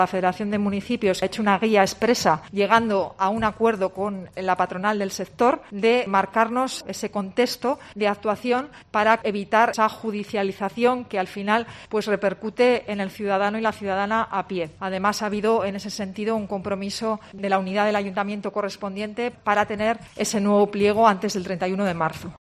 Eva Tobías, portavoz del Ayuntamiento de Logroño